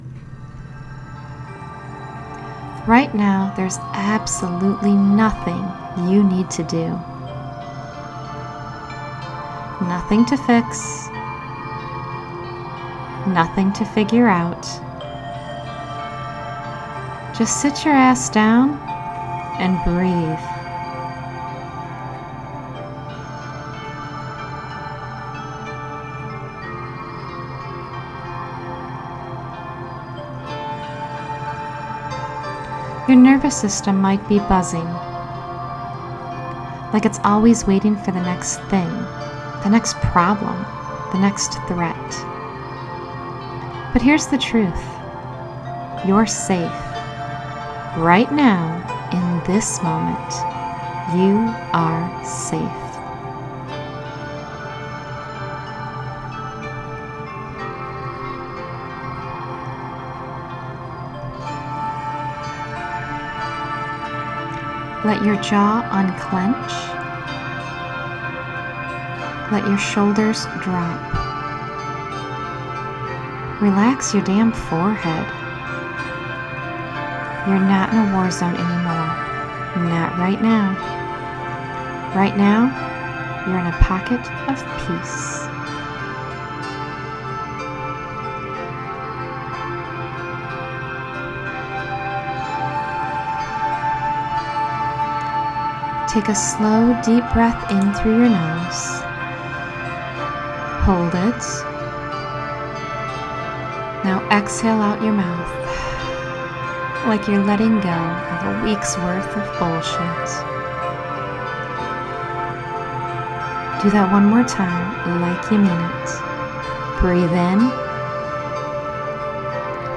Guided Audio Emotional Resets
This guided audio reset is Reiki-infused.
** Warning: There is adult language in this audio.